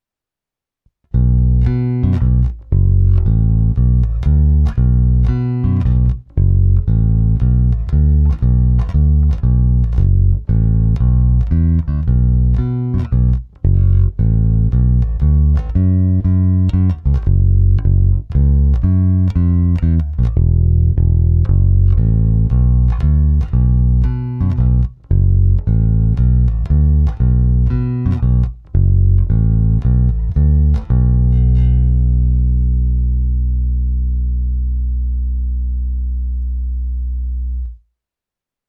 Dalo by se čekat, že zvuk bude klasický ala Fender Jazz Bass, ale není tomu úplně tak, pořád je to Fodera, nesjspíš i díky té aktivce, mimochodem na 18V.
Nahrávku jsem prohnal multiefektem Hotone Ampero, ale v něm mám zapnutou prakticky jen drobnou ekvalizaci a hlavně kompresor.
Krkový snímač - basy +50%, středy centr, výšky +50%